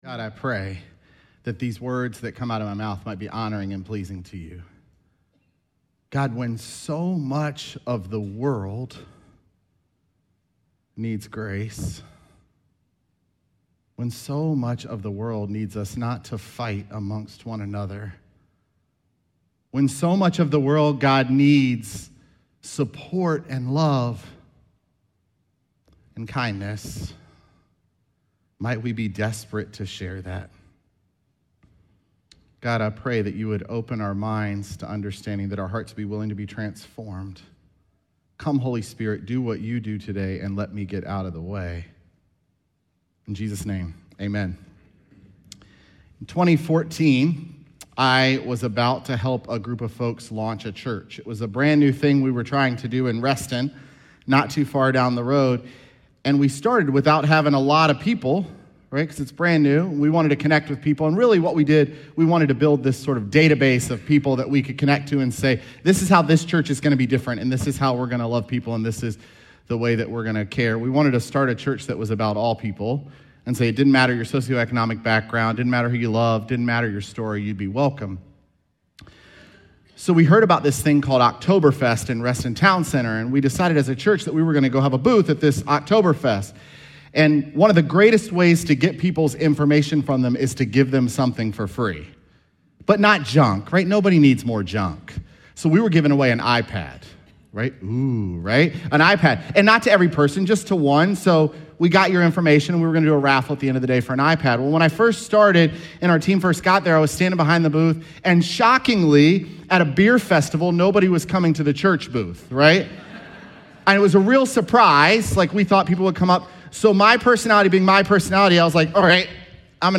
Sermons
Oct6SermonPodcast.mp3